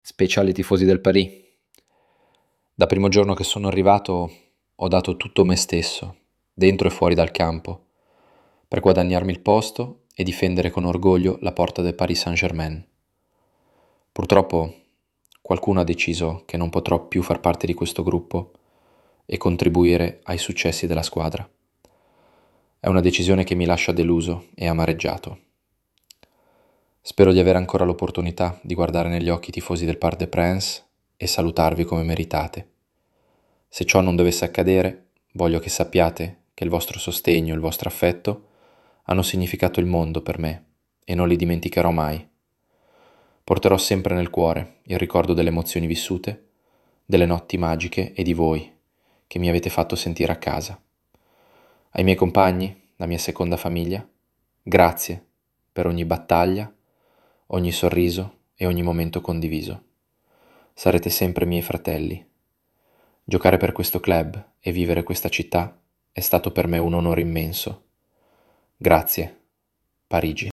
J’ai généré un fichier audio donna.mp3 avec eleven labs à partir du discours d’adieu de Donnarumma au PSG